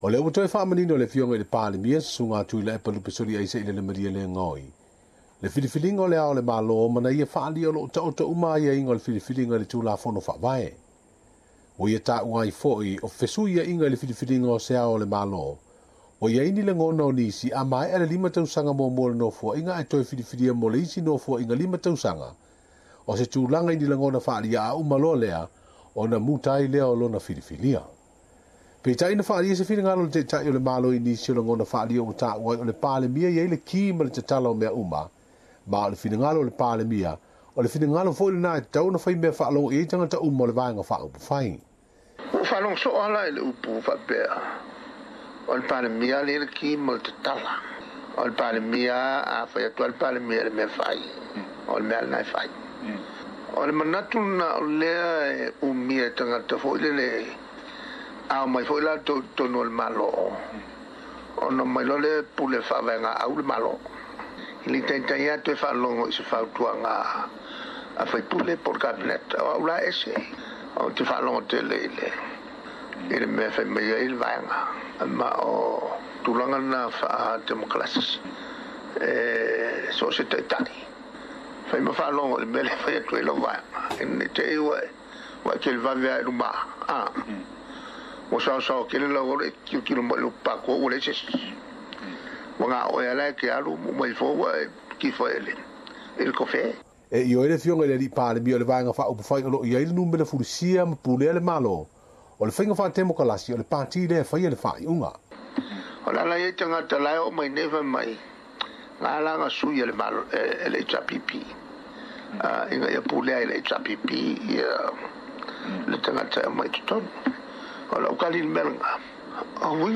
O loo teena e le palemia o Samoa Tuilaepa Sailele Malielegaoi lipoti sala tua o loo faapea mai o ia e tofia le Ao o le Malo ona o ia le taitai o le Malo e faalogo uma atu iai sui o lana vaega upufai. I se talanoaga ma tusitala, na saunoa foi le palemia o loo nisi o loo tuleia le mau e tatau ona faatapulaa i le lua nofoaiga, pe sefulu tausaga le umi e seei ai seisi i le tofi Ao o le Malo Samoa.